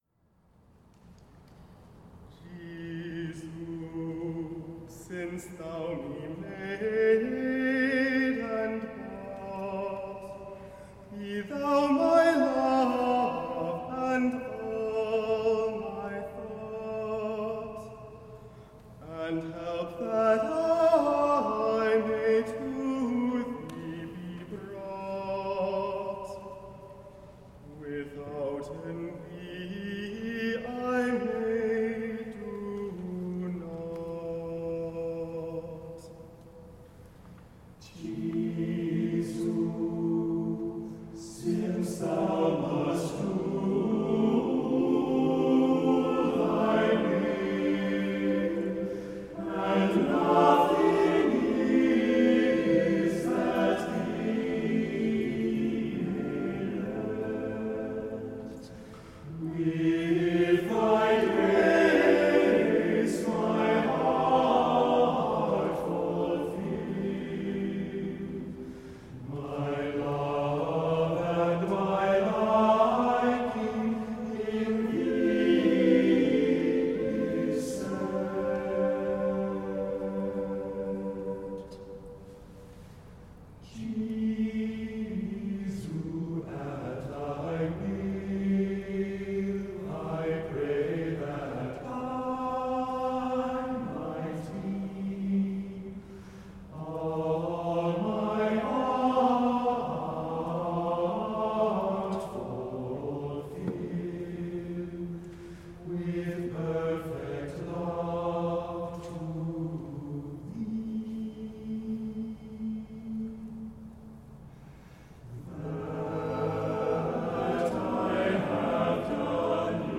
Text: Richard Rolle (1300-1349)
Music: George Oldroyd (1886-1951) sung by the Compline Choir, St. Mark’s Cathedral, Seattle (2015)